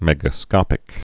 (mĕgə-skŏpĭk)